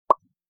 bubble_pop.wav